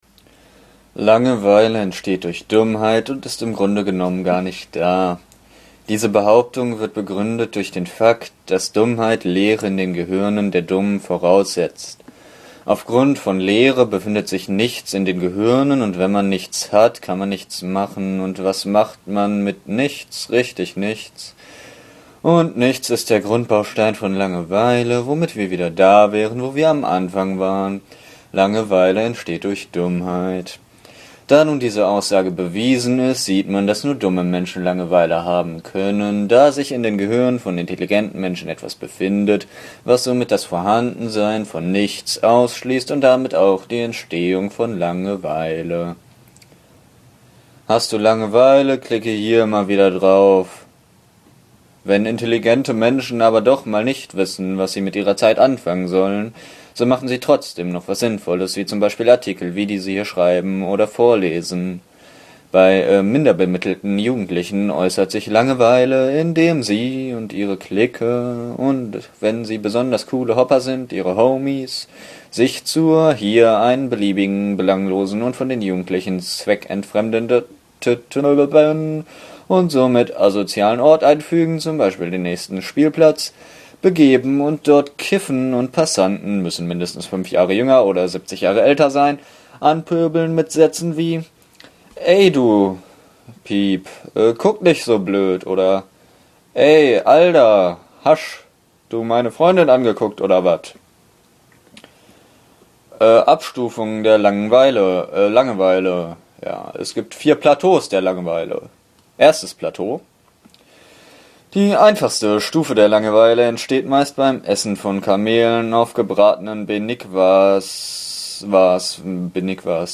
Kein Problem! Diesen Artikel gibt's nämlich auch als Audioversion für Analphabeten und die, die es werden wollen!